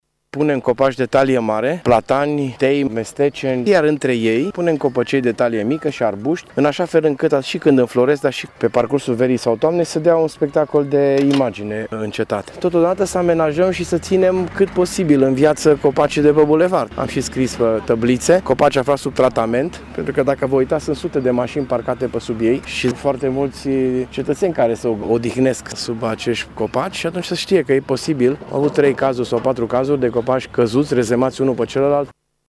Primarul Tîrgu-Mureșului, Dorin Florea, s-a referit și la protejarea copacilor de pe B-dul Cetății: